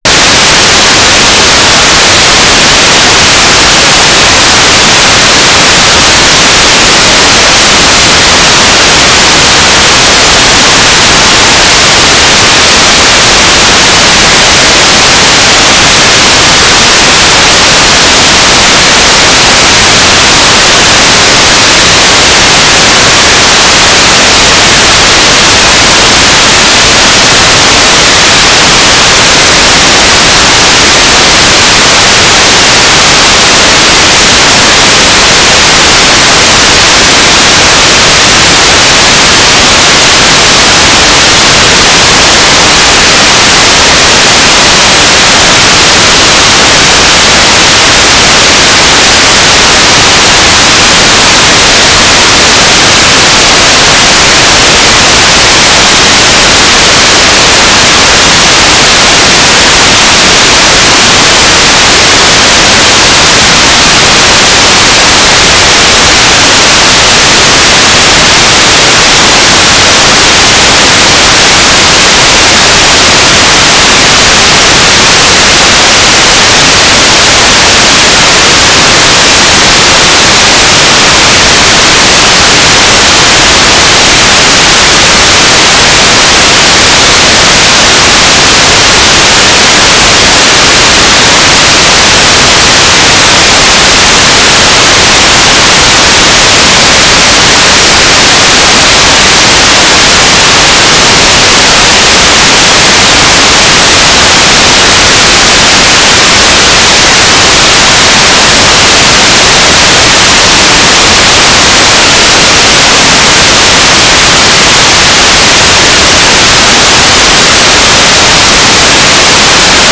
"waterfall_status": "without-signal",
"transmitter_description": "Mode U - 9k6 Transmitter",
"transmitter_mode": "GMSK",